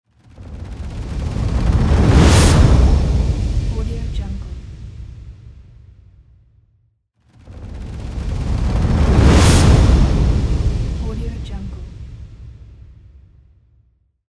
دانلود افکت صوتی whoosh 29
این افکت باعث ایجاد حس ترس و هیجان می‌شود.
Sample rate 16-Bit Stereo, 44.1 kHz
Looped No